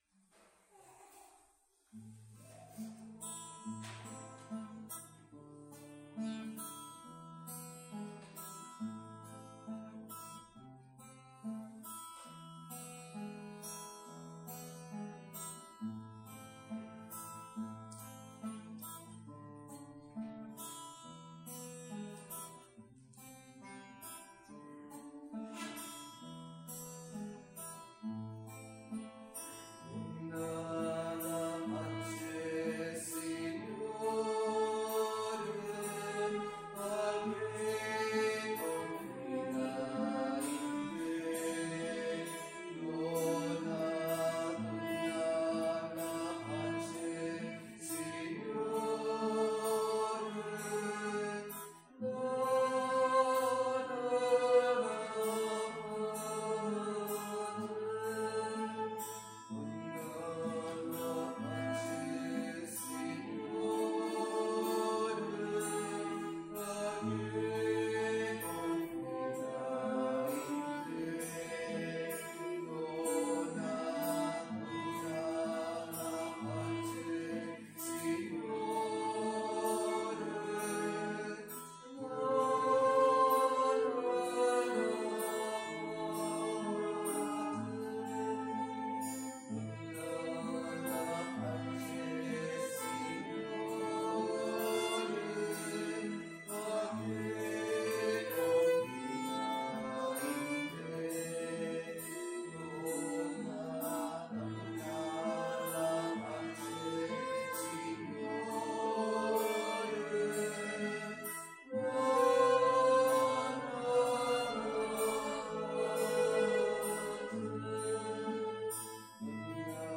Pregària de Taizé a Mataró... des de febrer de 2001
Parròquia M.D. de Montserrat - Diumenge 27 de febrer de 2022